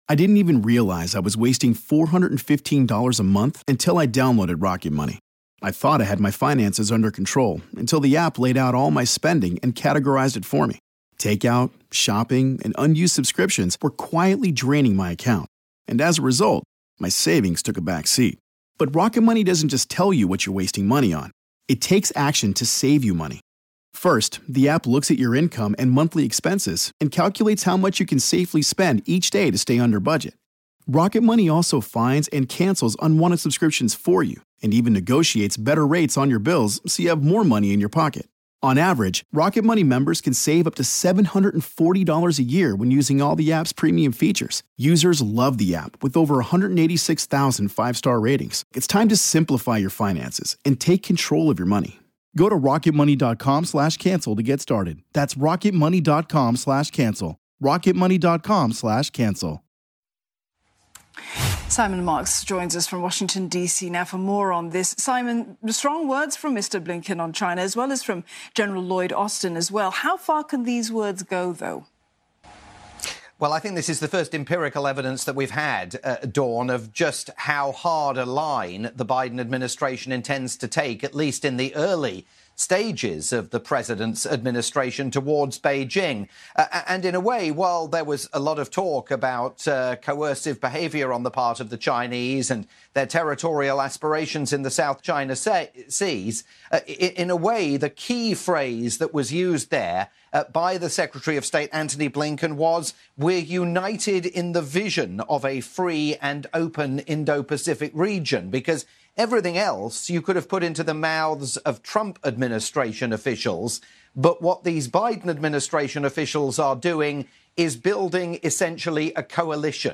live report for CNA